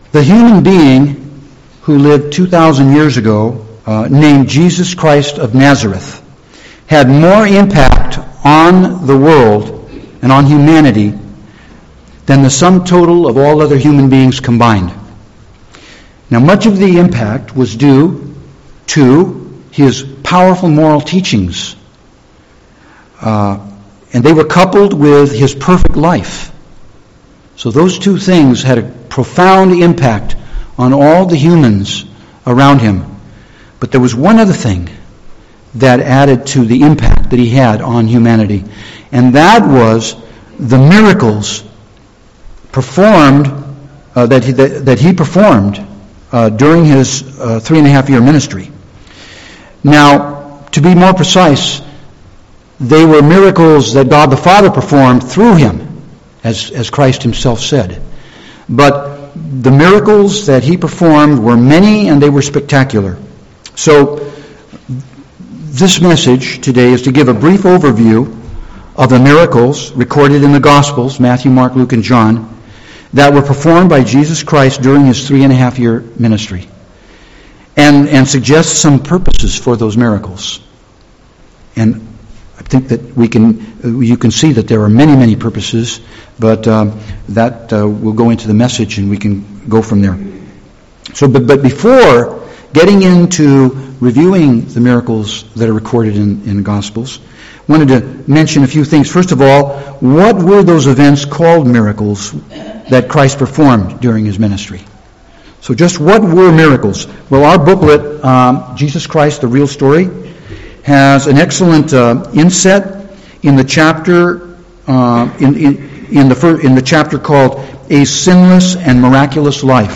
Given in San Jose, CA